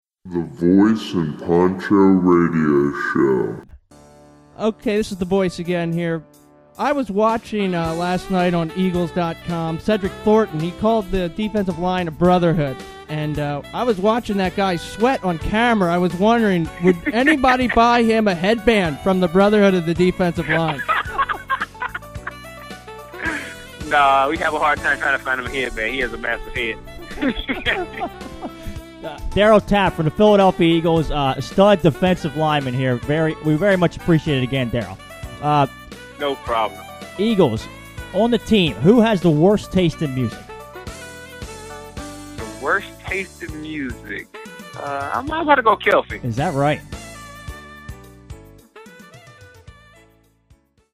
Darryl Tapp Interview with V&P Preview